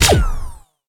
.开火2.ogg